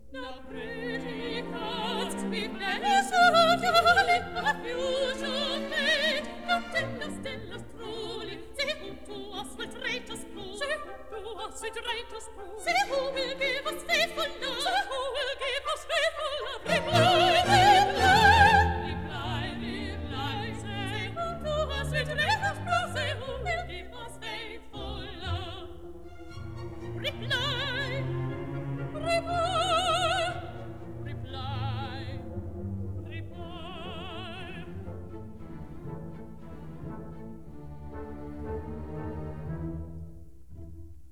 sopranos
mezzo-soprano